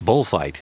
Prononciation du mot bullfight en anglais (fichier audio)
Prononciation du mot : bullfight